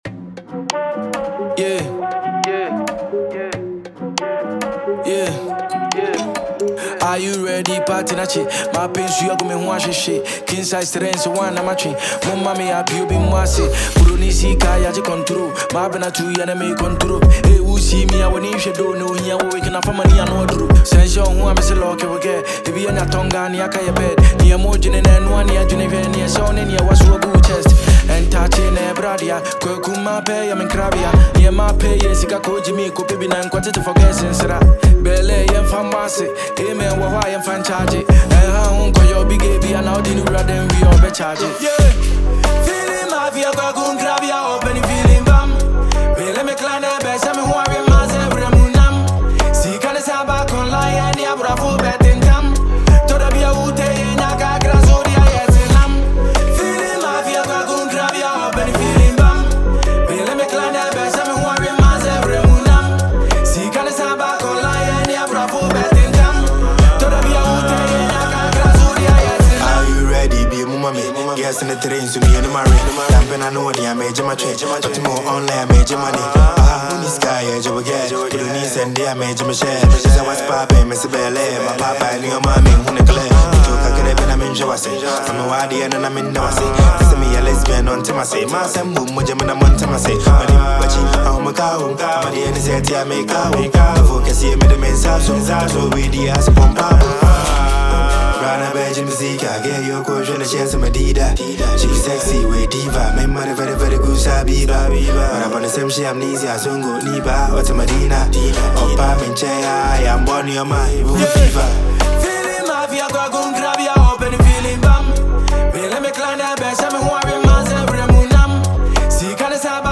Ghana Music Music
His flow is smooth but commanding